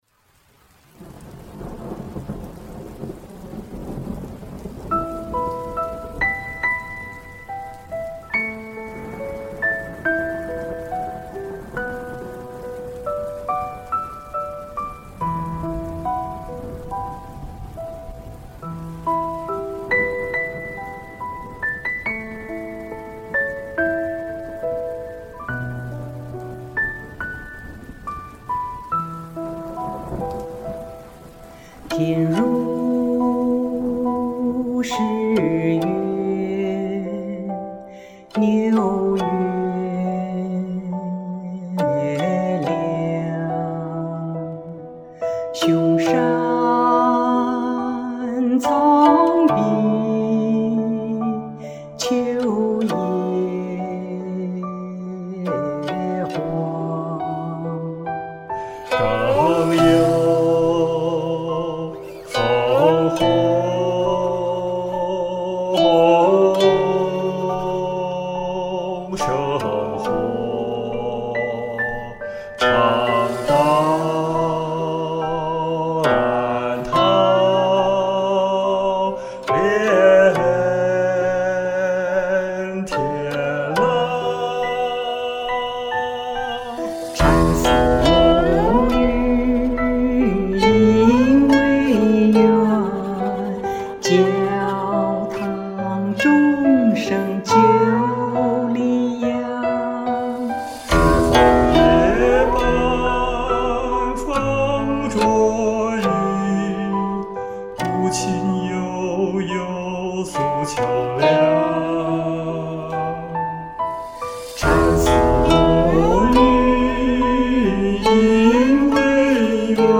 古琴演奏
和聲
朗誦
雷雨聲隆隆
古風跨越地域，古琴悠悠，古韻悠悠。。。
實錄的古琴含蓄不張揚卻餘音悠遠